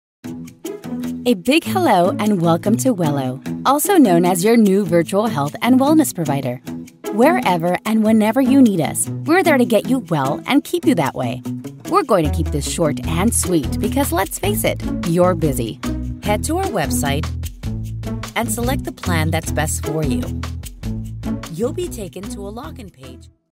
Female Voice Over, Dan Wachs Talent Agency.
Bilingual Voice Actor.
eLearning- English